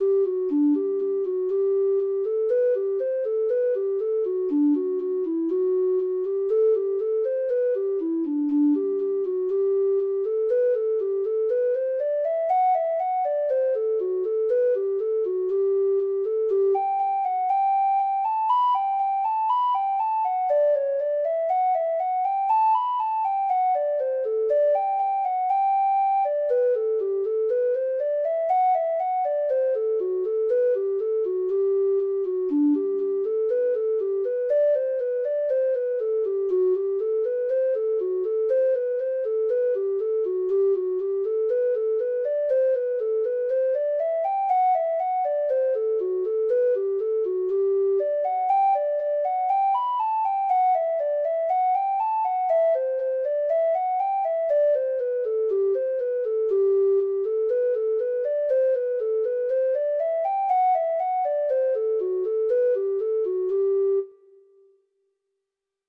Treble Clef Instrument version
Reels